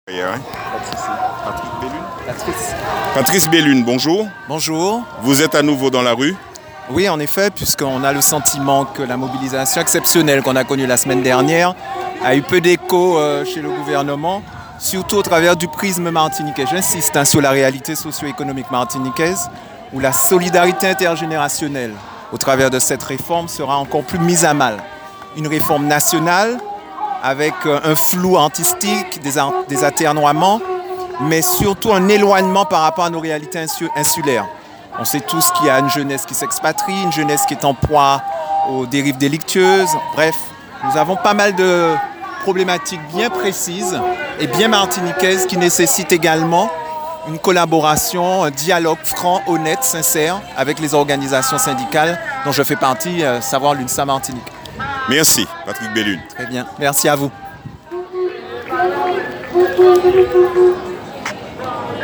Ecoutez les différents responsables de syndicats que nous avons interrogés .